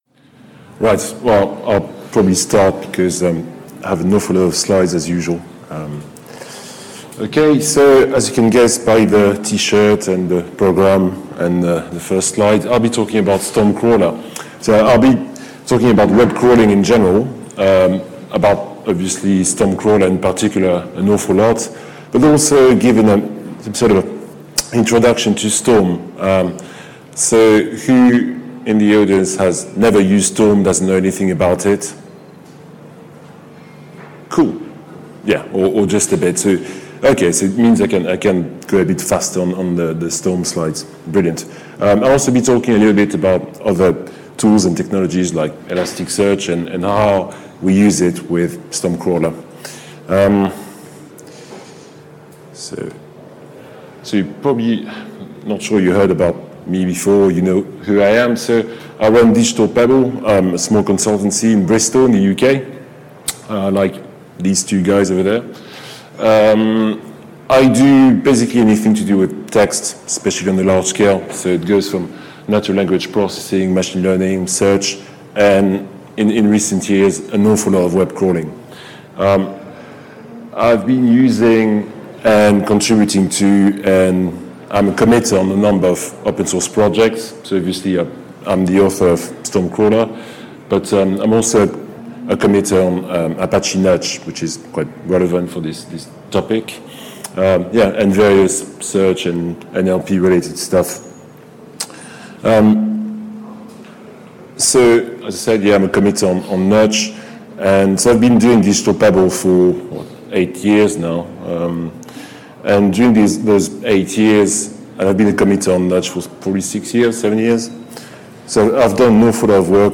Apache Big Data Seville 2016